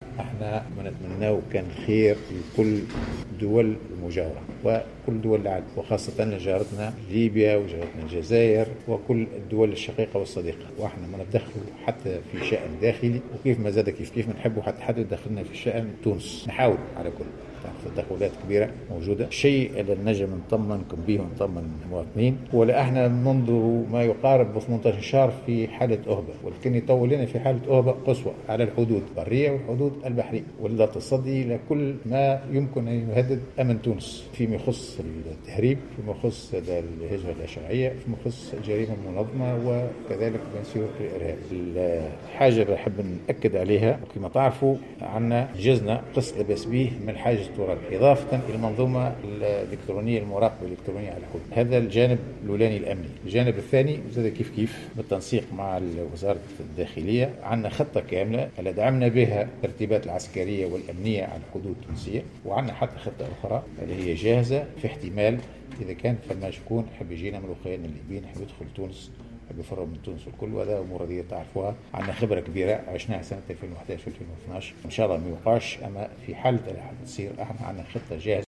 وأضاف في تصريح لمراسل الجوهرة اف ام، على هامش تدشينه قاعة العمليات لمنظومة المراقبة الإلكترونية في ولاية قابس، أنه تم وضع خطة كاملة بالتنسيق مع وزارة الداخلية لدعم الترتيبات الأمنية والعسكرية على الحدود التونسية الليبية كما تم وضع خطة أخرى جاهزة لاستقبال المواطنين الليبيين.